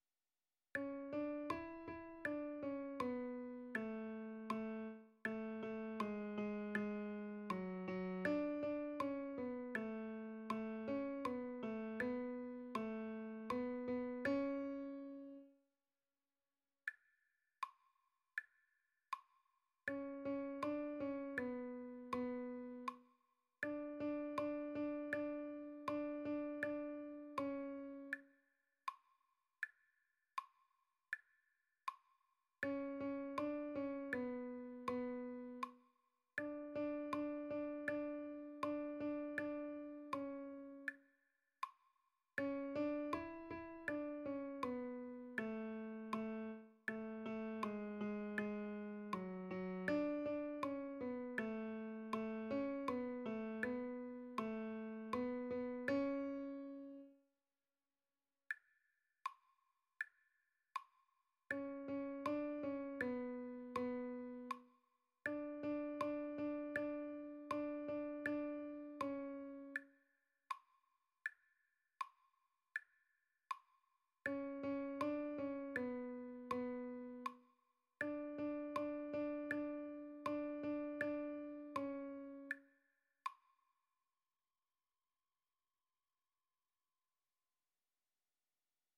R�p�tition de la pi�ce musicale N� 775
Comme ton regard mon autre_tenor.mp3